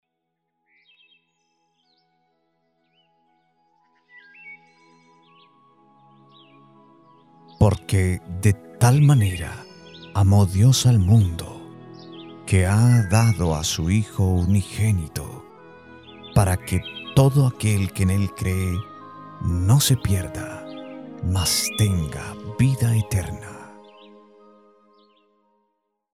Masculino
Espanhol - América Latina Neutro
Cercana, Intima